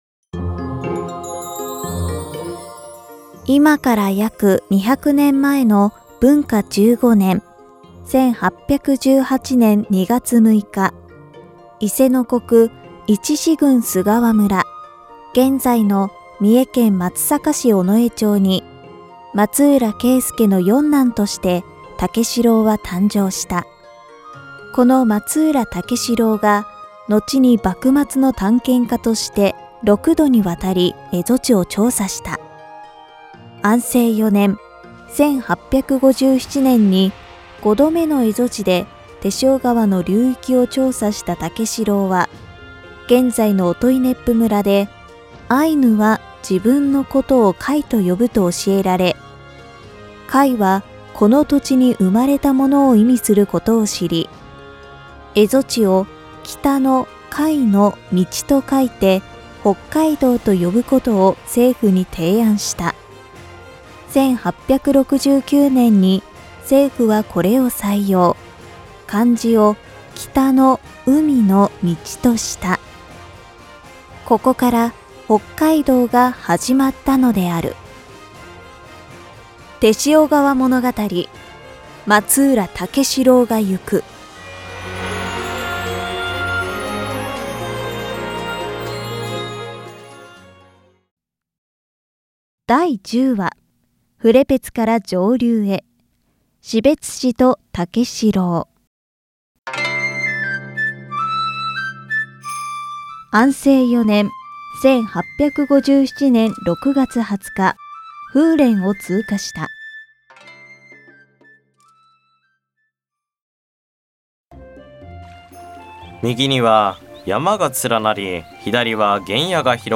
解説